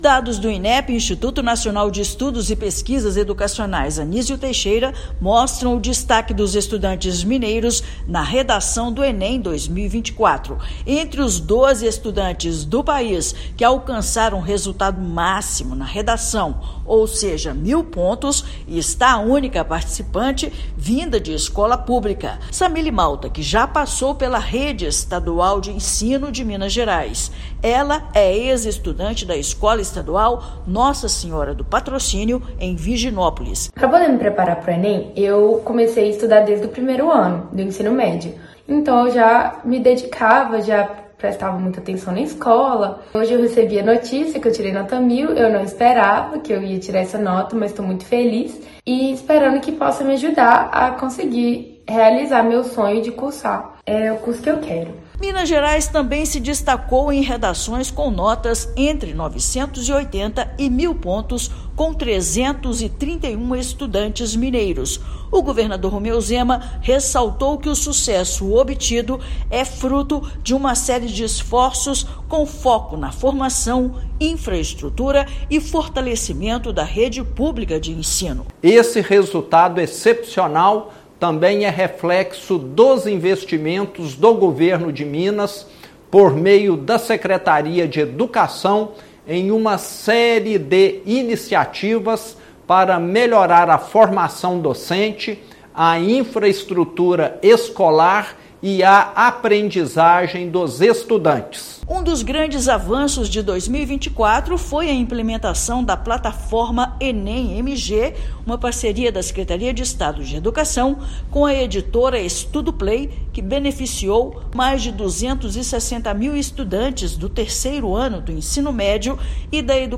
Com investimentos em preparação pedagógica, apoio logístico e tecnologia para os estudos, alunos da rede estadual superaram desafios e se destacaram no exame nacional; estudante mineira também foi a única de escola pública do país a alcançar pontuação máxima. Ouça matéria de rádio.